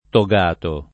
togato [ to g# to ]